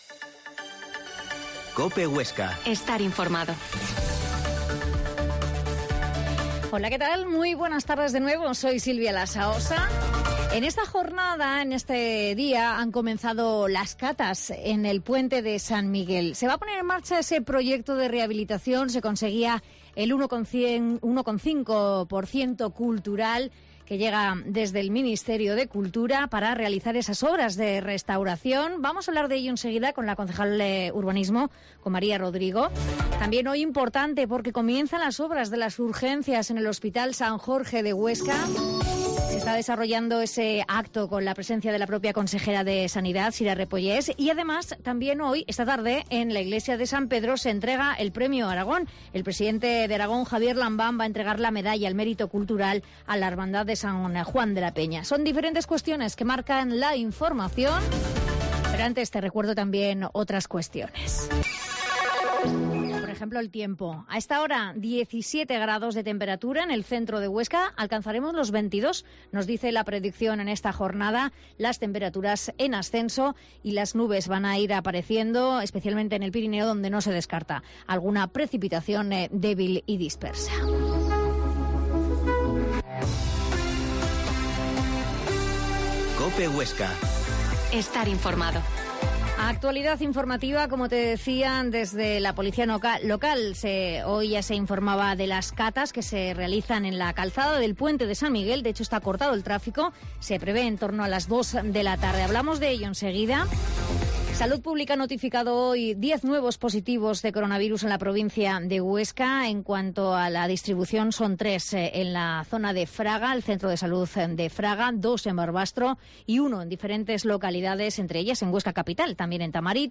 Entrevista a la responsable de Urbanismo del Ayuntamiento de Huesca